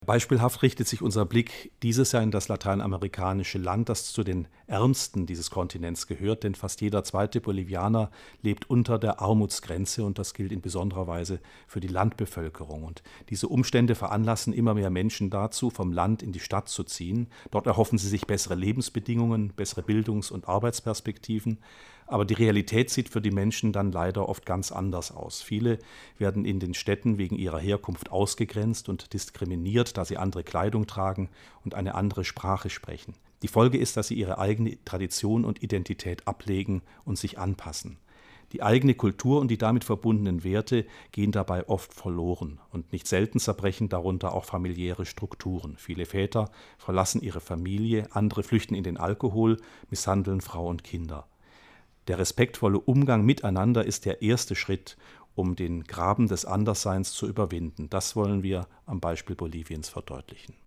Interview Freiwillige 2017